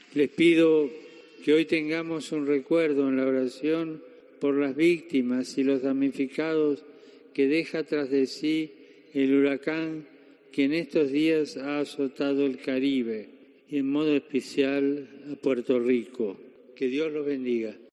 Francisco aplaudió y agradeció durante la audiencia general celebrada en la plaza de San Pedro la iniciativa de Caritas Internationalis y otras organizaciones católicas que son "símbolo de una Iglesia abierta".